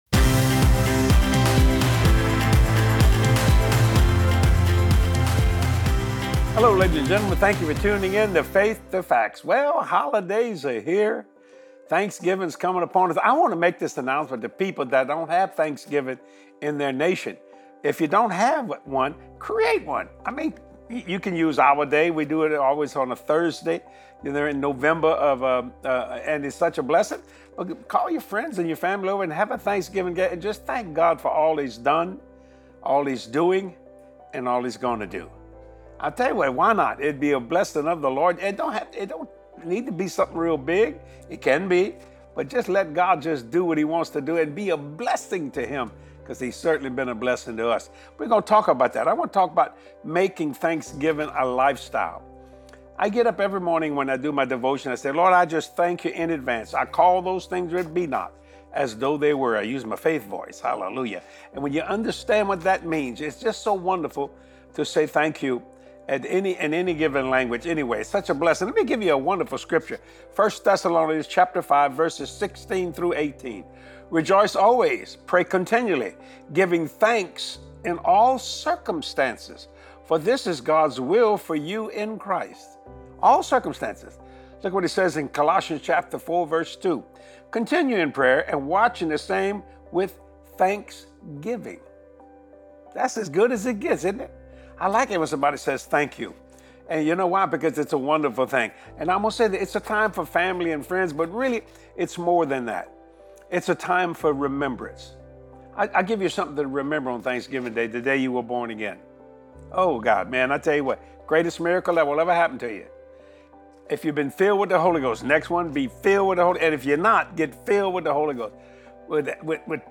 It’s always the perfect time to say THANK YOU to God! Get full of faith as you watch this power-packed teaching from Jesse.